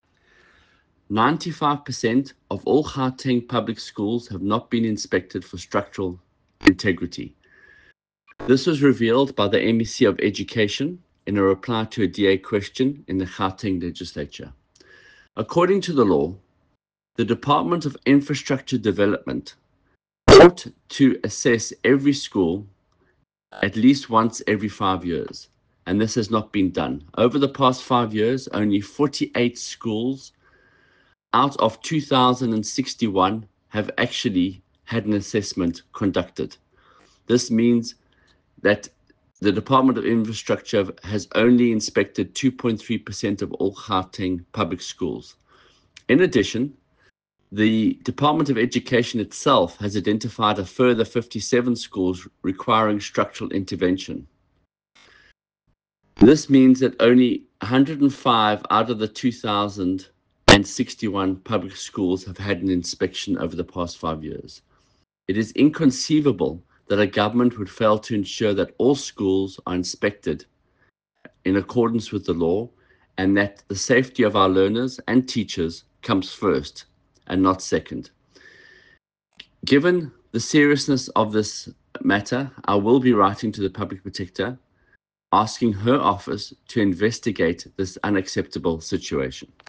Issued by Michael Waters MPL – DA Gauteng Spokesperson for Education
soundbite by Michael Waters MPL.